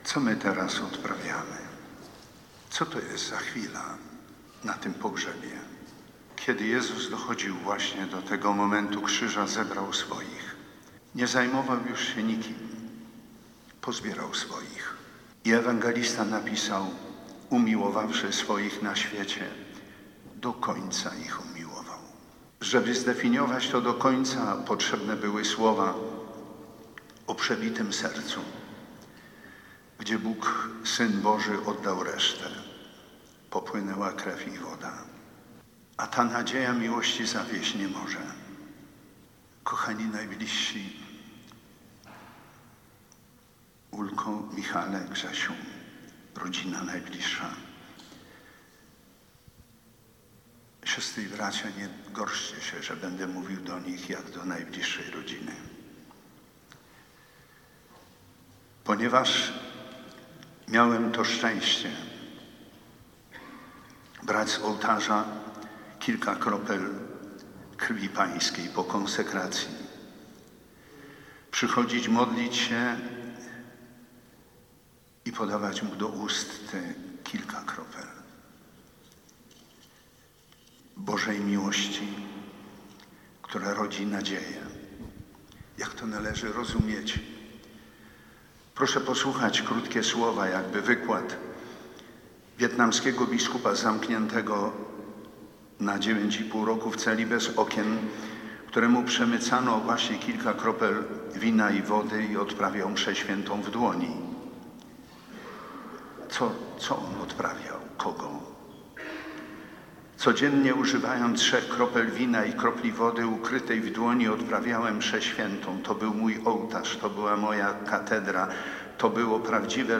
Mszy św. pogrzebowej w koszalińskiej katedrze przewodniczył bp Edward Dajczak.
Bp Edward Dajczak w bardzo osobistym kazaniu uwypuklił w postaci zmarłego prezydenta jego wiarę.
bpDajczak kazanie Mikietyński.mp3